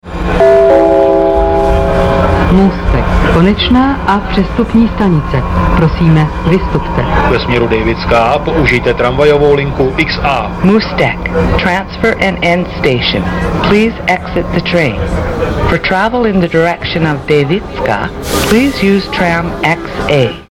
- Hlášení "Můstek, konečná stanice..." si